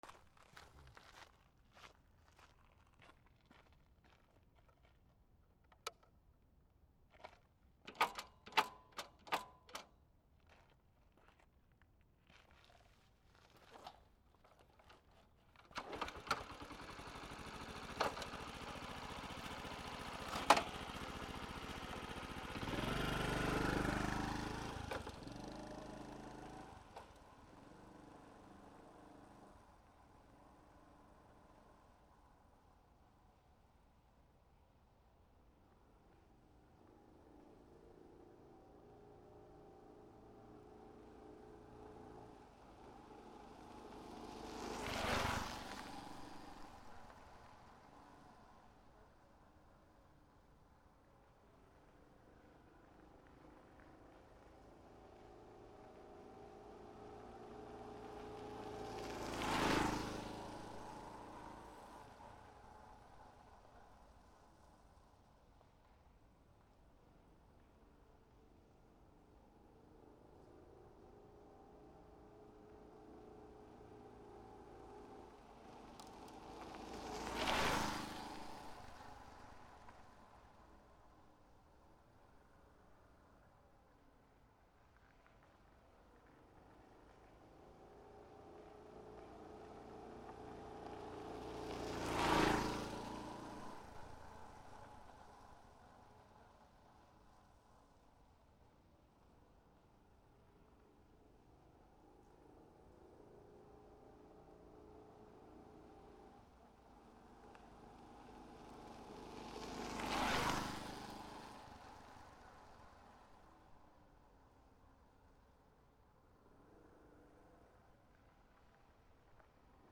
/ E｜乗り物 / E-30 ｜バイク / カブ録音
89横を通過